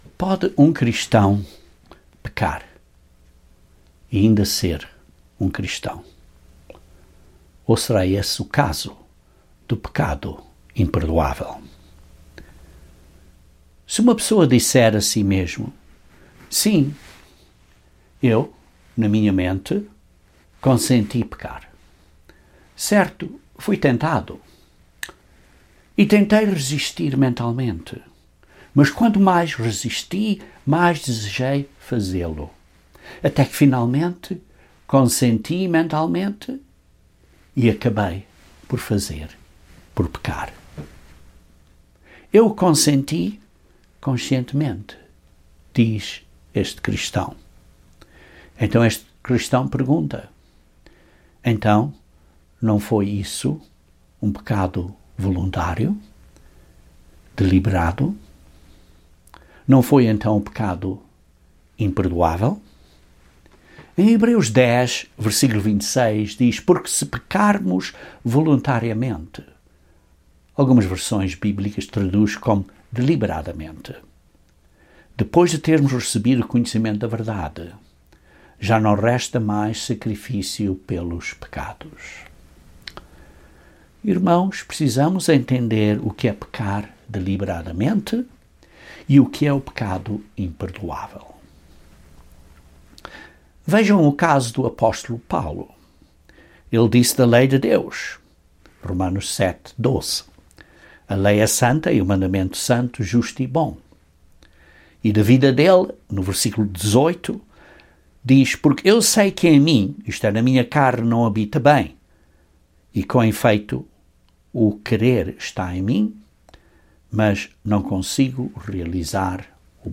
Quando é que um pecado se torna um pecado imperdoável? Este mini-sermão descreve brevemente este tema.